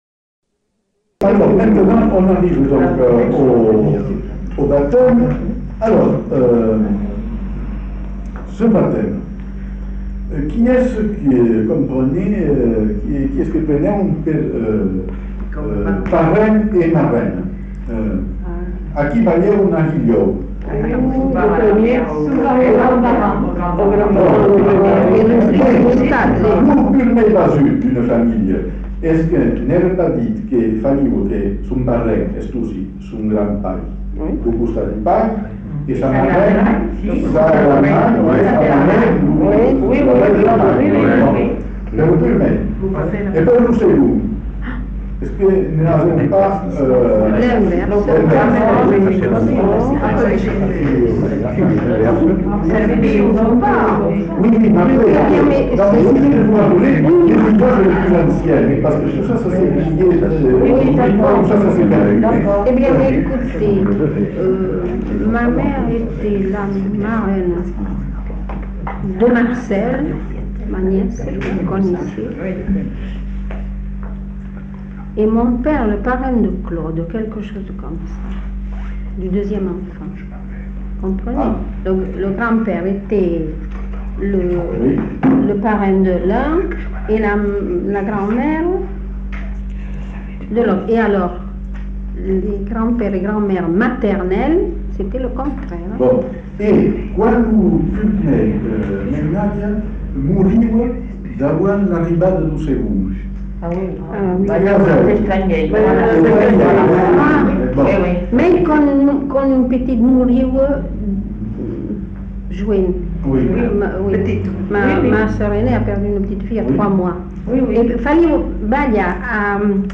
Lieu : Bazas
Genre : témoignage thématique
Témoignage sur la petite-enfance avec formulettes enfantines